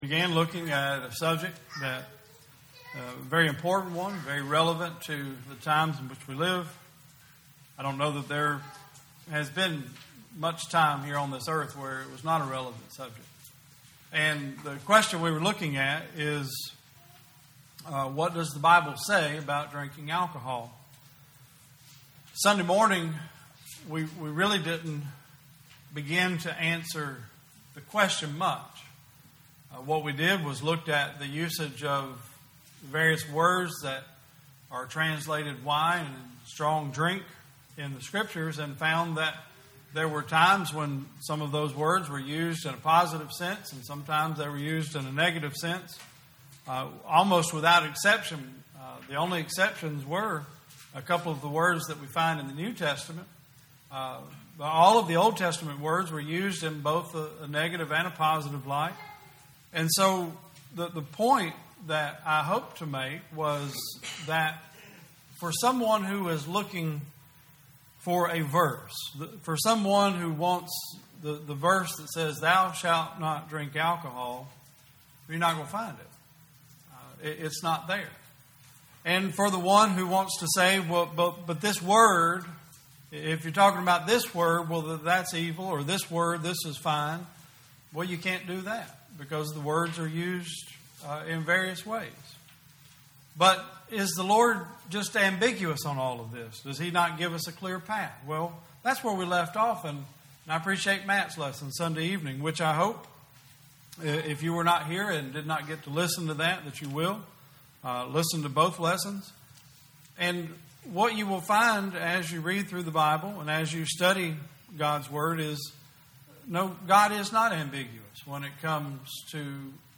2019 Service Type: Sunday Service Topics: Alcohol , Drinking , Wine « How To Make A Difference Who Warned You to Flee From the Wrath to Come?